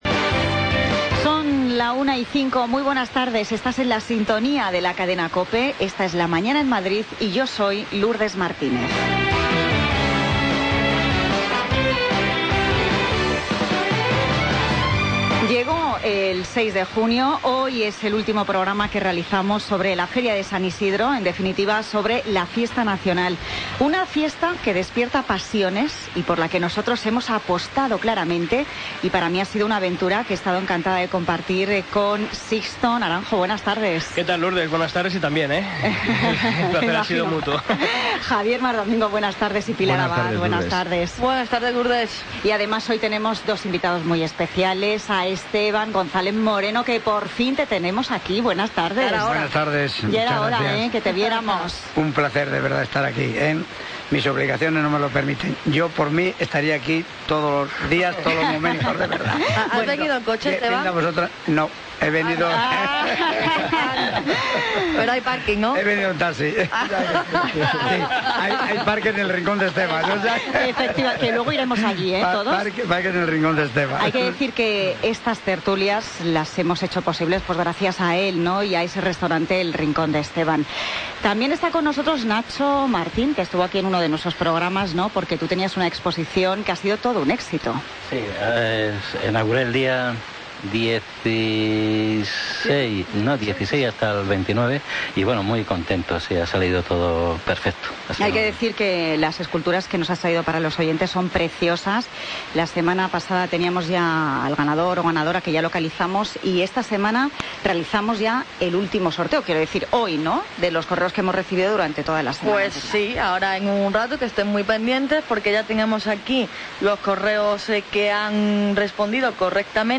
Tertulia Taurina Feria San Isidro COPE Madrid, lunes 6 de junio de 2016